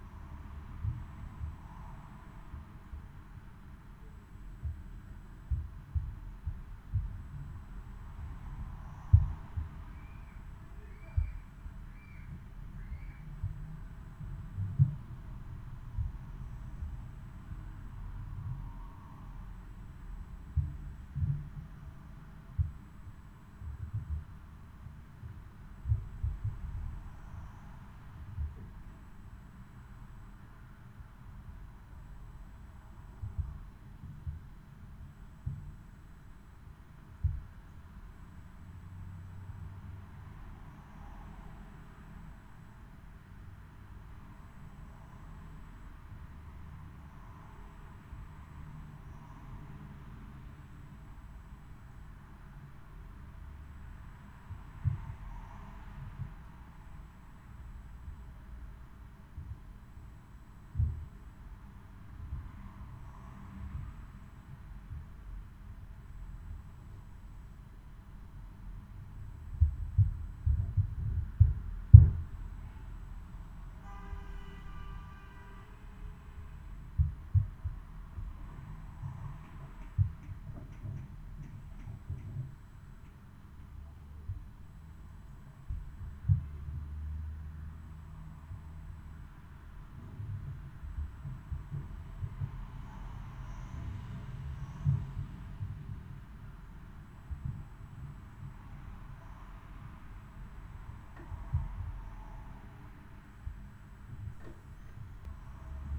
Das Mikrofon zeigt leicht schräg in 63 cm Entfernung auf die Decke.
Trittschallereignisse
Diese Aufnahme beinhaltet ein Poltern mit einem Pegel von 65 db(A), ungefähr eine Minute nach Beginn der Aufnahme.
Es ist wie ein Knall mit einem unerträglichen Klang, das ich mir von oben kommend anhören muss.